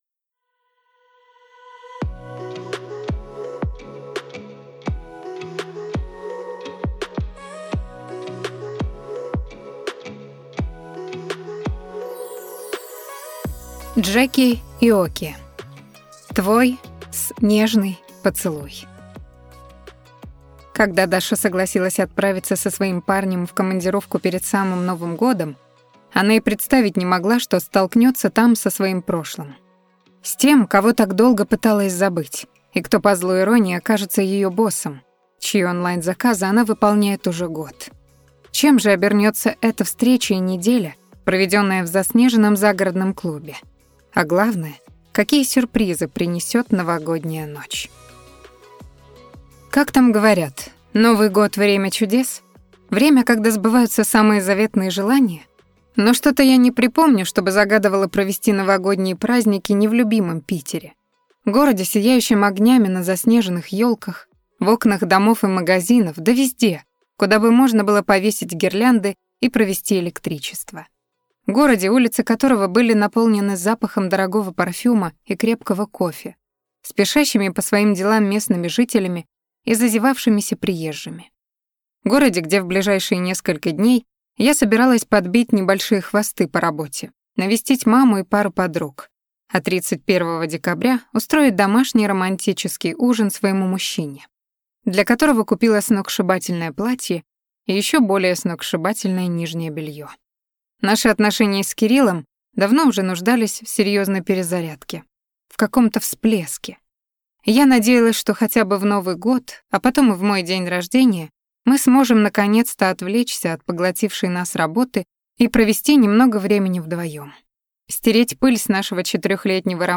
Аудиокнига Твой (с)нежный поцелуй | Библиотека аудиокниг